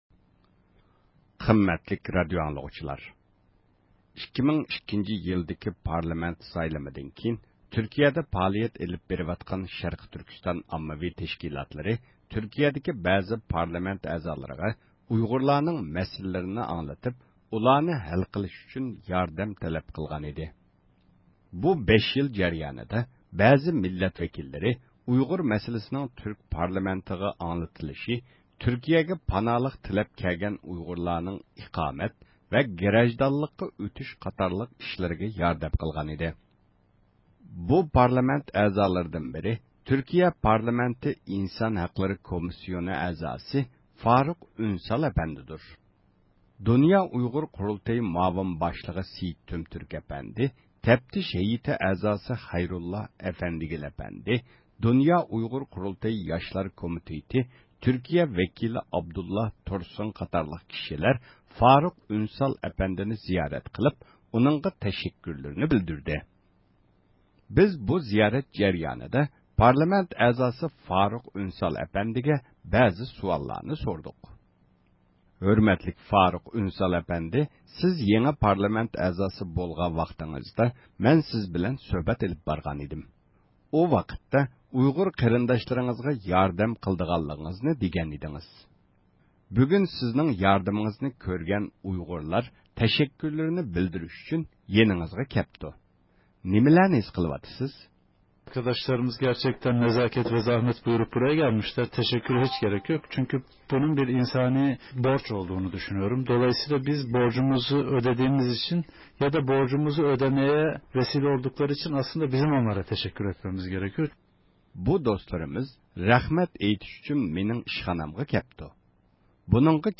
بىز بۇ زىيارەت جەريانىدا پارلامېنت ئەزاسى فارۇك ئۈنسال ئەپەندىدىن بەزى سۇئاللارنى سورىدۇق.